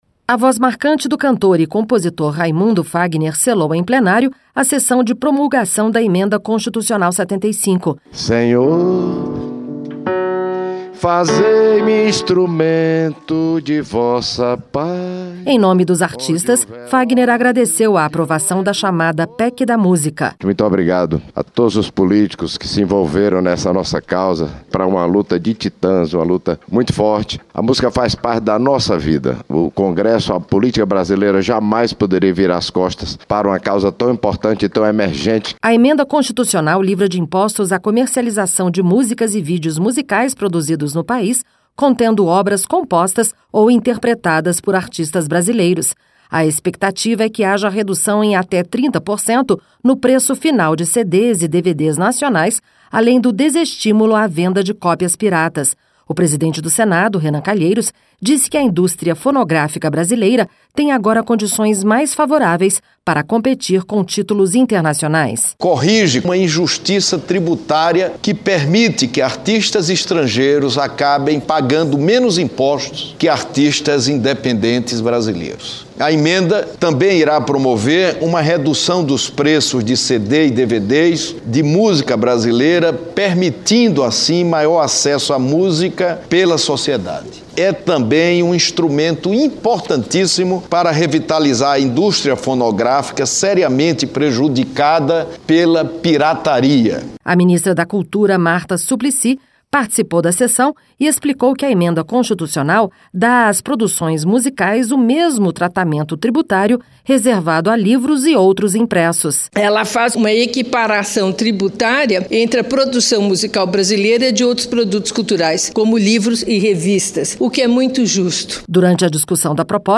TÉC: A voz marcante do cantor e compositor Raimundo Fagner selou, em plenário, a sessão de promulgação da Emenda Constitucional 75 (FAGNER)